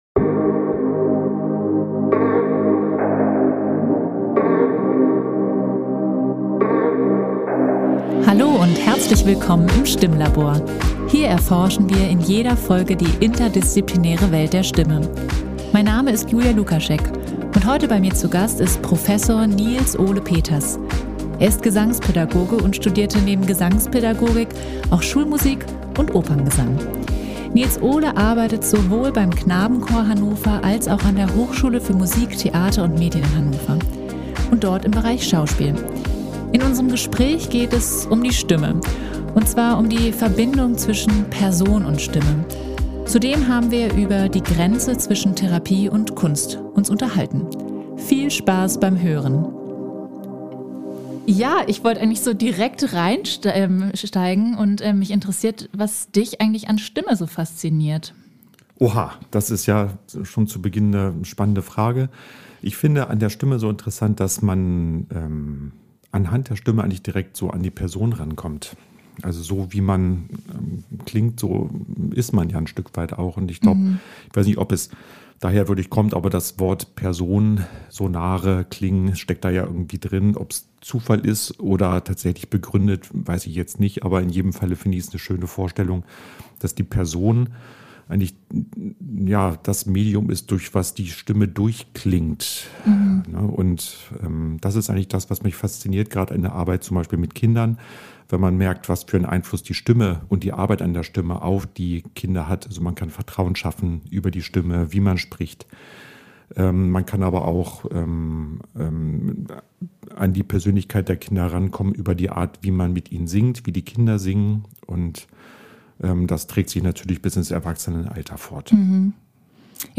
In unserem Gespräch geht es um die Stimme, und zwar um die Verbindung von Person und Stimme. Zudem haben wir uns über die Grenze zwischen Therapie und Kunst unterhalten.